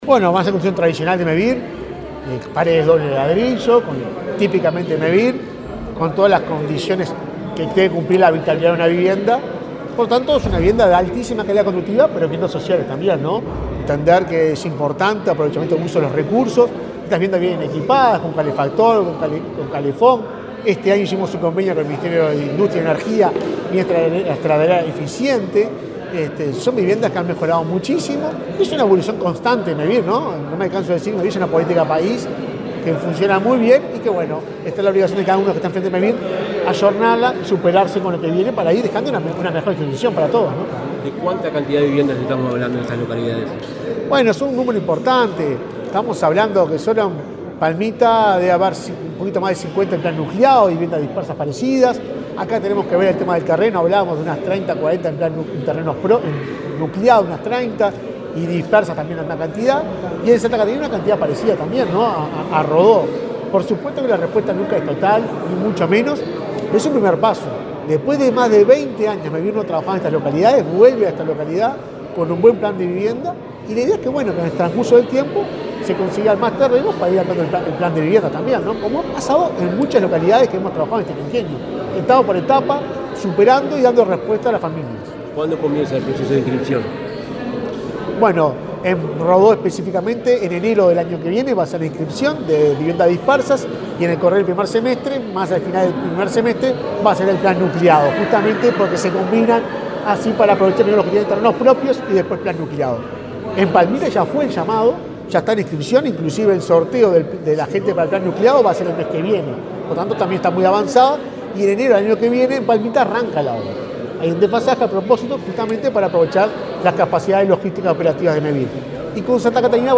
Declaraciones del presidente de Mevir en Soriano
El presidente de Mevir, Juan Pablo Delgado, dialogó con la prensa en José Enrique Rodó, departamento de Soriano, donde anunció programas de viviendas.